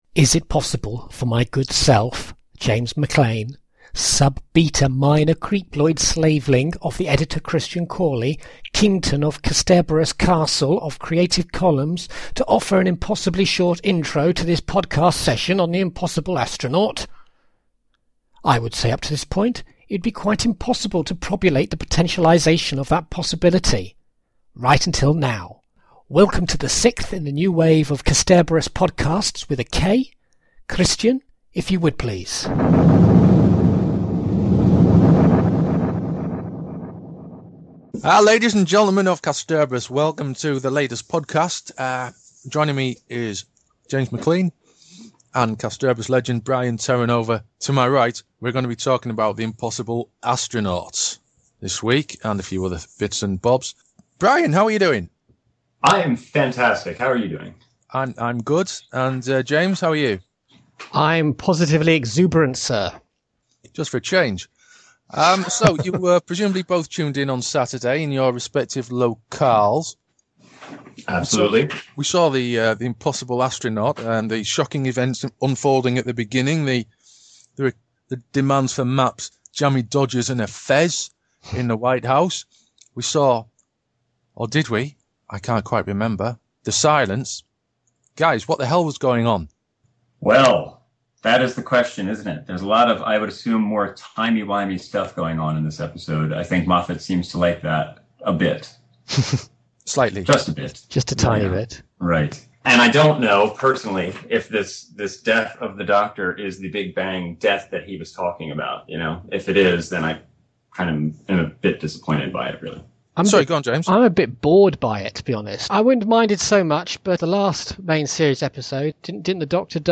in a wide-ranging discussion